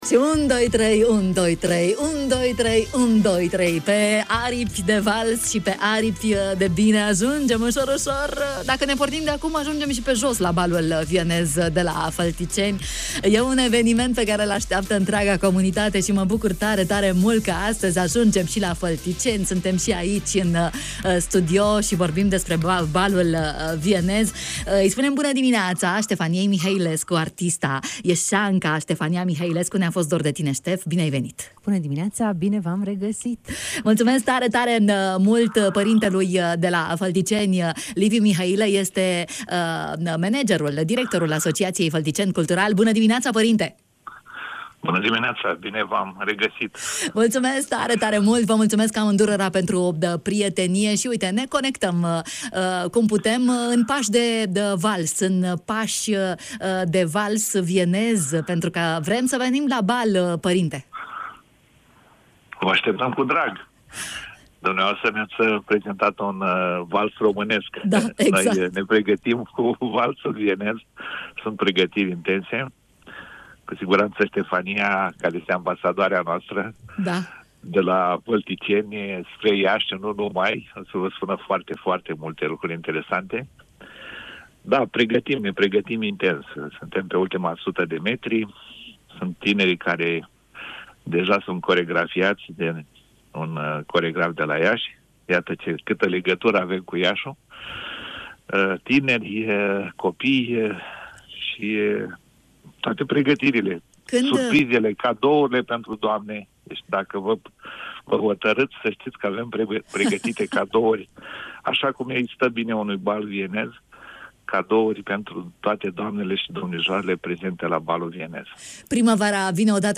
în direct la Radio Iași